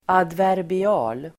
Ladda ner uttalet
Uttal: [advärbi'a:l]